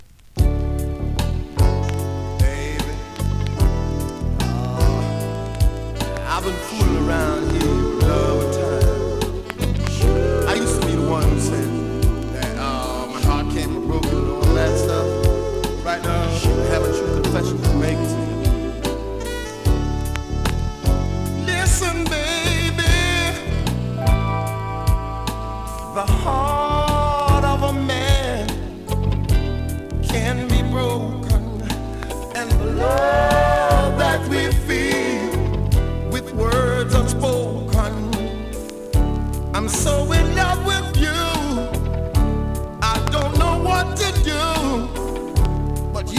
DANCEHALL!!
スリキズ、ノイズ比較的少なめで